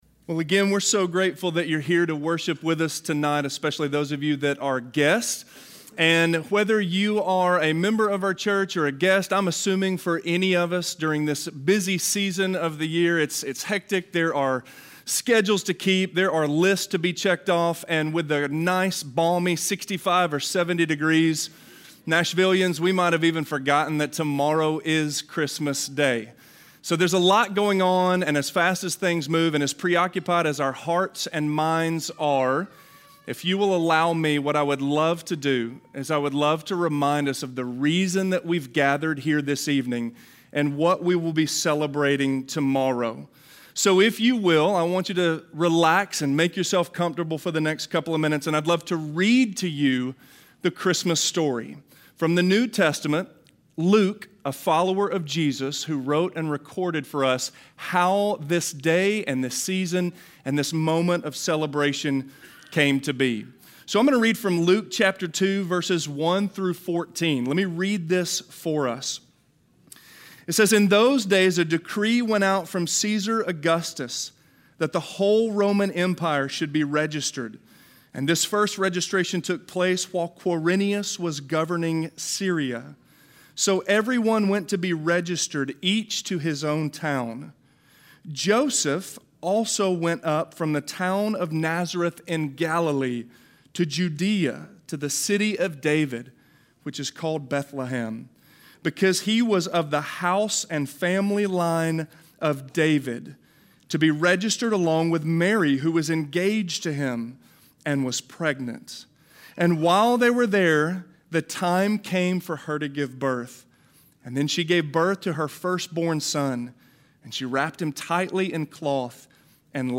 The Ultimate Christmas - Sermon - Avenue South